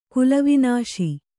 ♪ kulavināśi